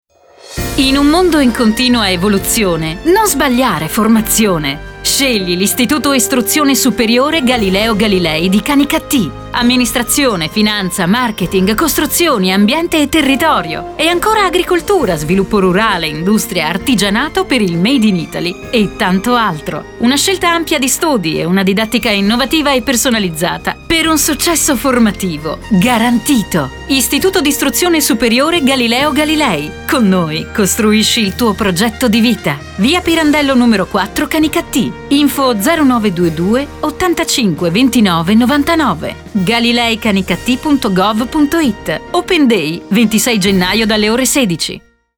SPOT RADIOFONICO – IISS Galileo Galilei – Canicattì